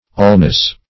Search Result for " allness" : The Collaborative International Dictionary of English v.0.48: Allness \All"ness\, n. Totality; completeness.